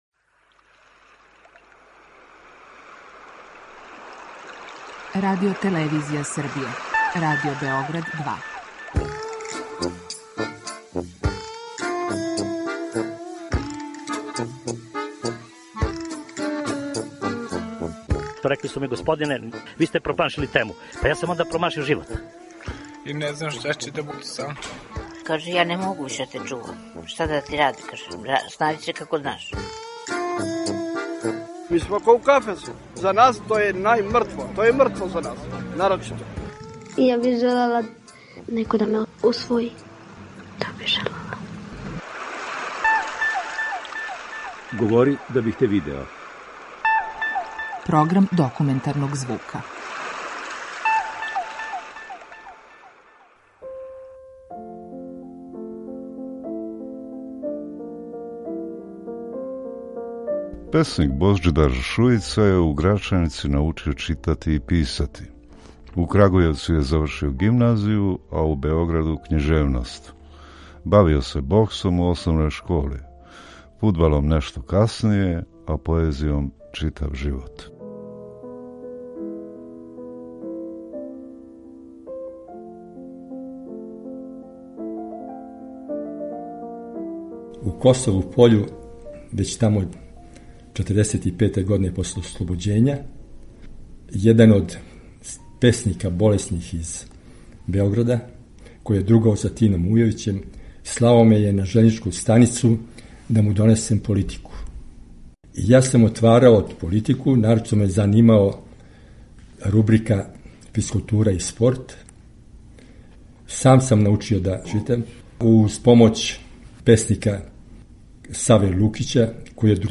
Документарни програм
Група аутора Серија полусатних документарних репортажа